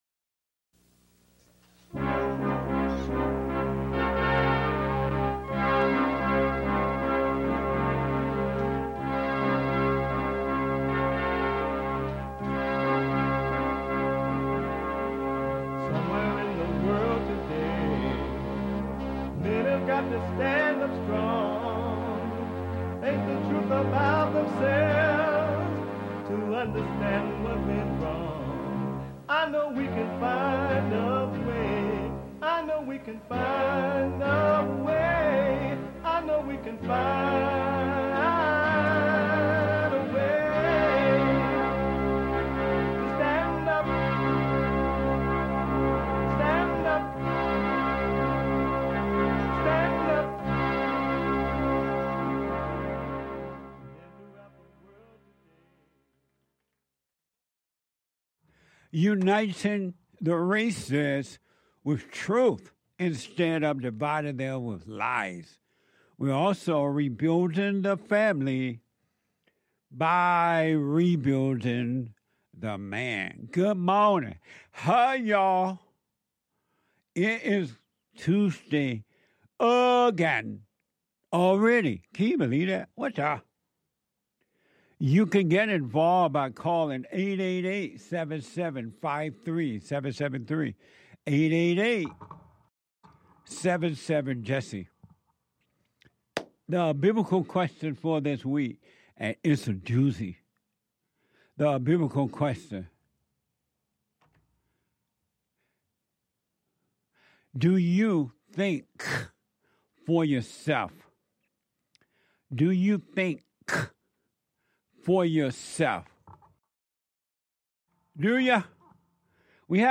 Daily News